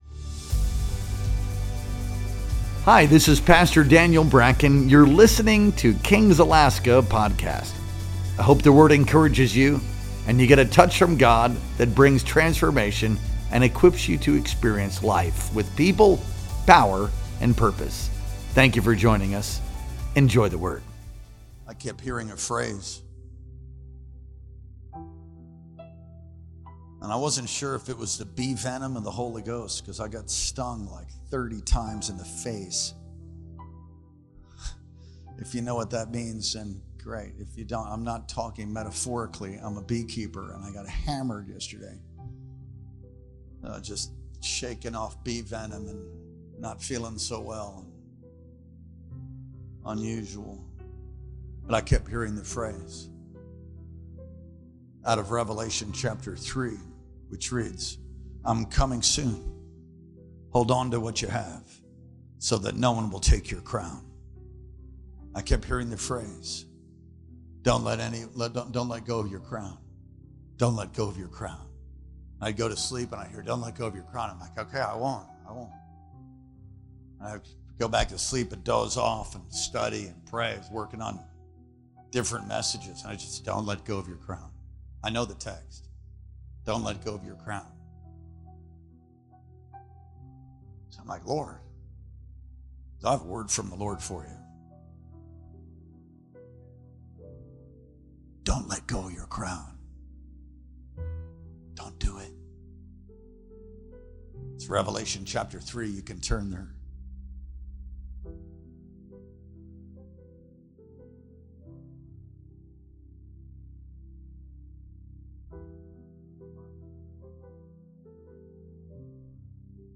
Our Wednesday Night Worship Experience streamed live on April 16th, 2025.